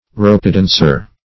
Ropedancer \Rope"dan`cer\, n.